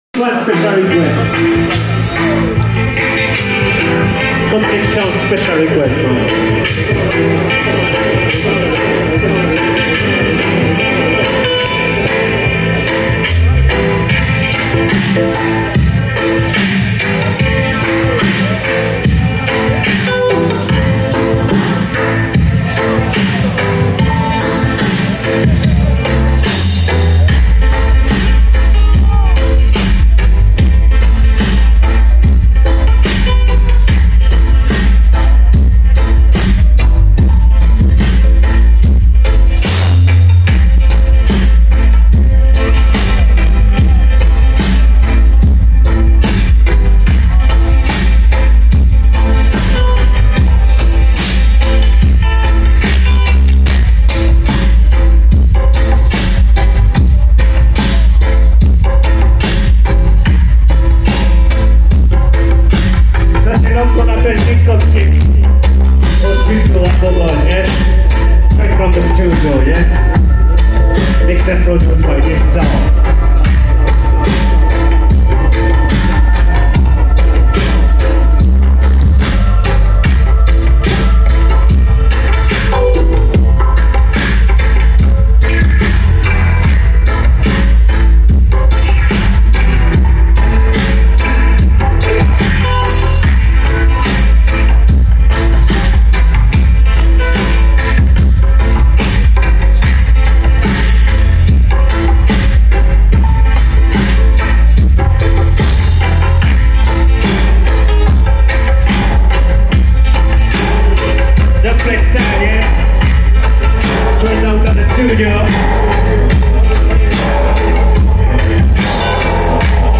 Live session